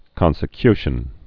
(kŏnsĭ-kyshən)